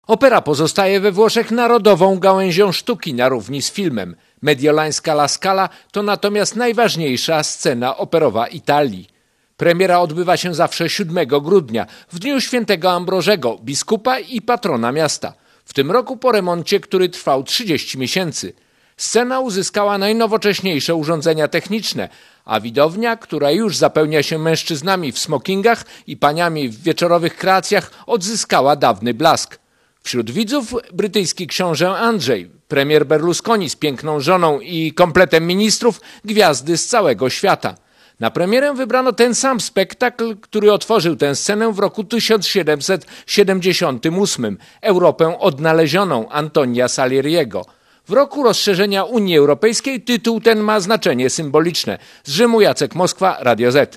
Korespondencja z Włoch